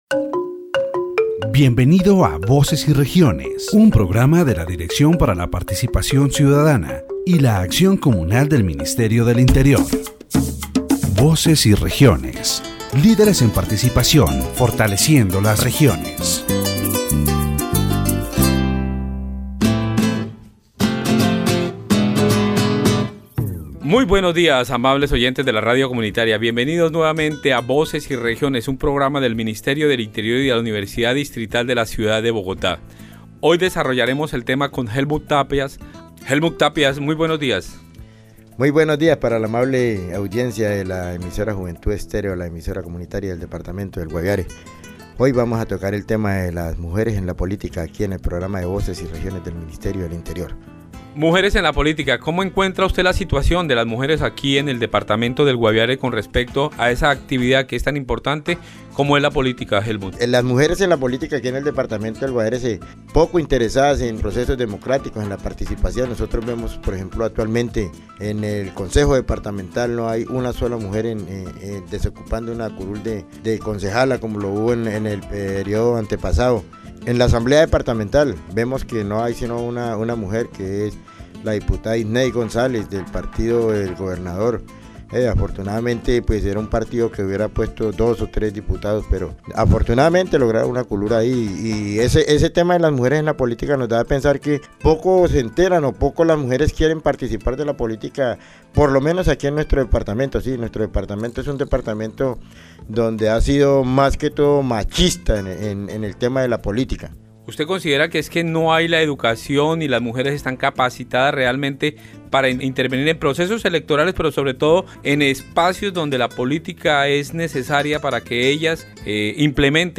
The interview concludes with an encouragement for women to register and take part in local councils, assemblies, and departmental decision-making bodies, emphasizing the importance of their active participation in governance.